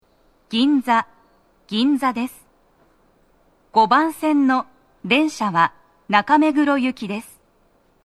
足元注意喚起放送の付帯は無く、フルの難易度は容易です
女声
到着放送1